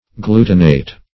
Search Result for " glutinate" : The Collaborative International Dictionary of English v.0.48: Glutinate \Glu"ti*nate\, v. t. [imp.